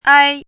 ai1.mp3